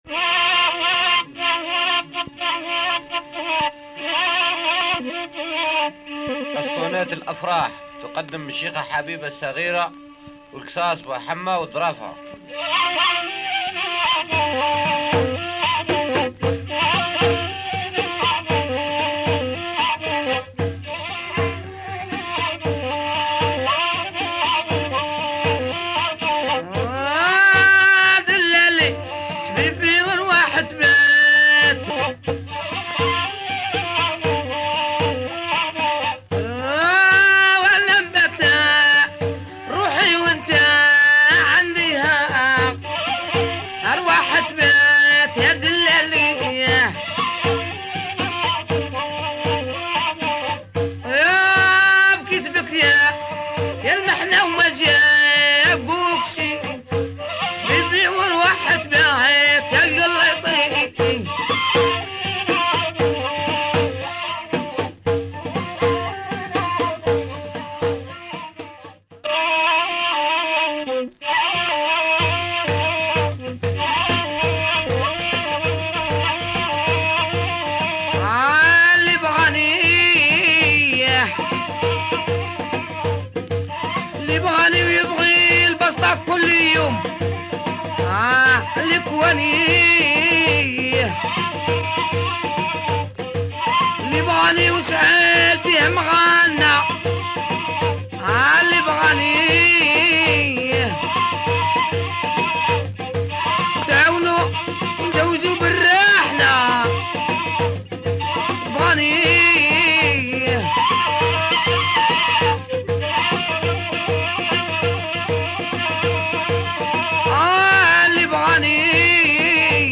Very rare female raw chants from Algeria.